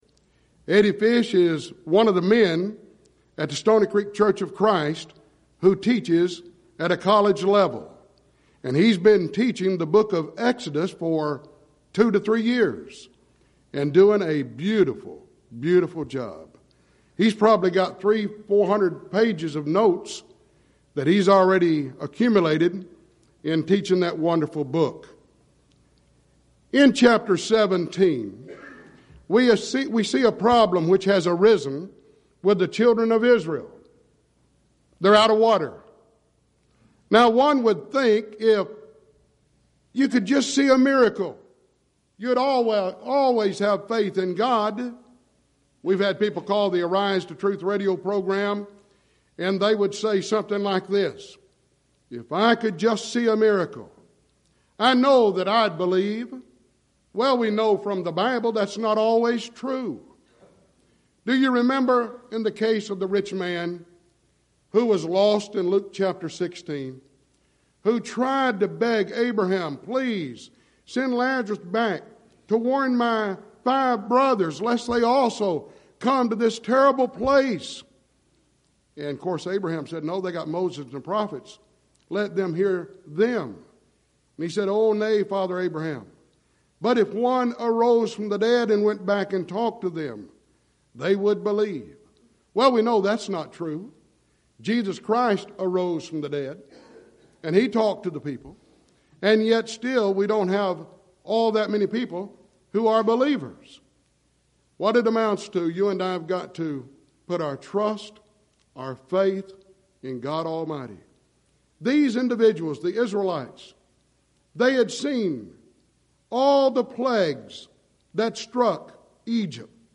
Event: 2nd Annual Schertz Lectures Theme/Title: Studies In Exodus